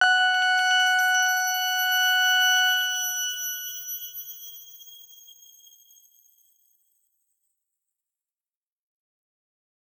X_Grain-F#5-ff.wav